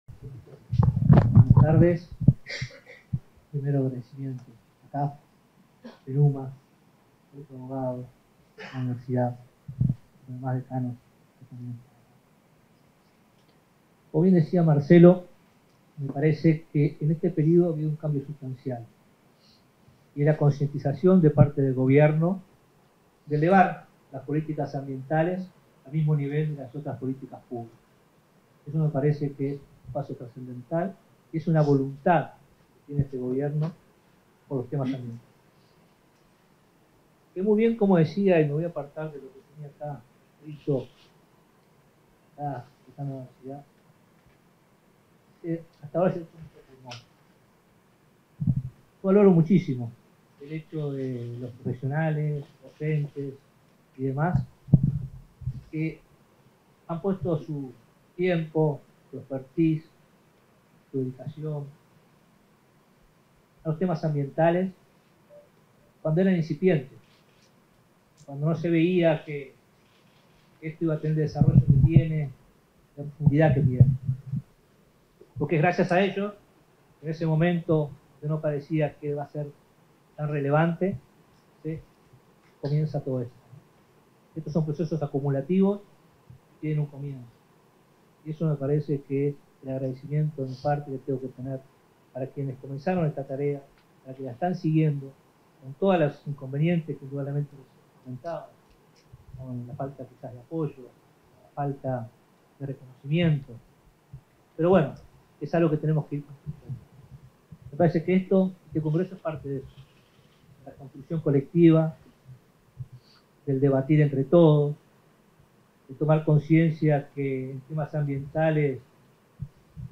Palabras del ministro de Ambiente, Robert Bouvier
En la apertura del tercer Congreso Uruguayo de Derecho Ambiental, este 27 de agosto, se expresó el ministro de Ambiente, Robert Bouvier.